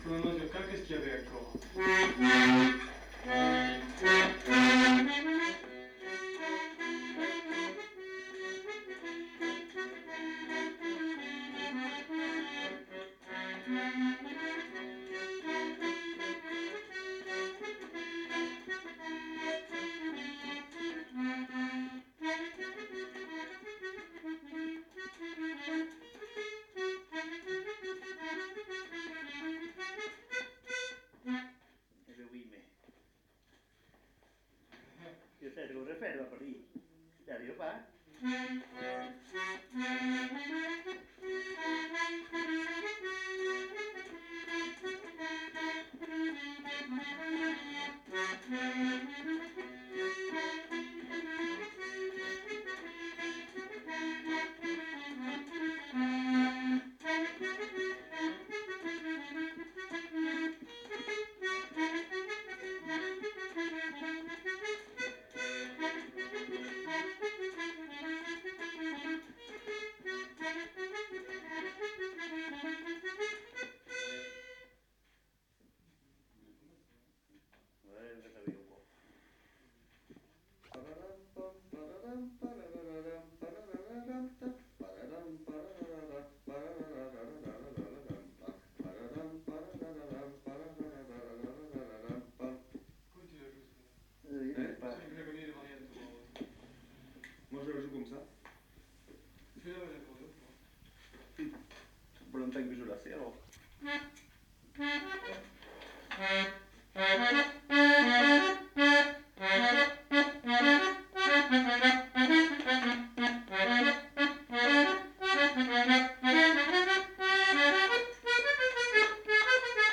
Aire culturelle : Petites-Landes
Genre : morceau instrumental
Instrument de musique : accordéon diatonique
Danse : mazurka